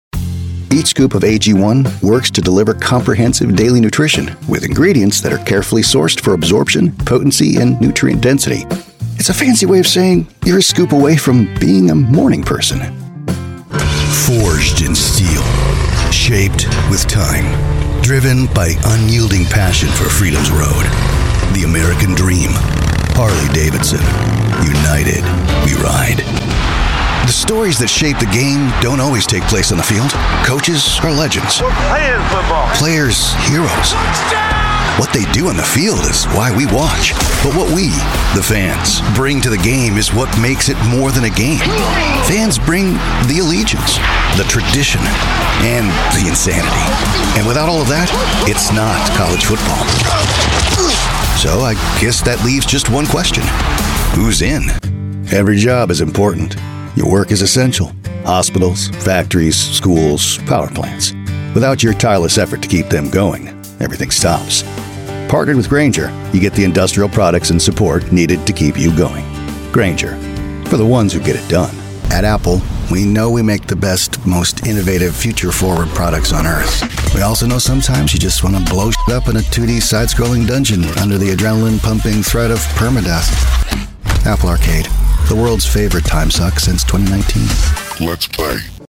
Easy-going, Guy-Next-Door, Conversational.
Commercial